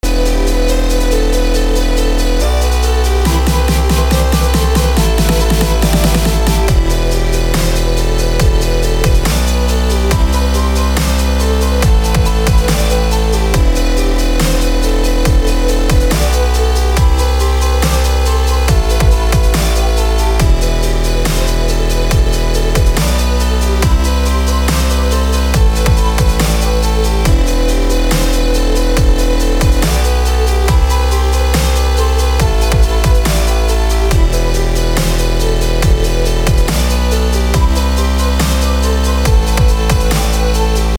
• Качество: 320, Stereo
громкие
жесткие
Electronic
без слов
Trap
качающие
Крутая качающая trap музыка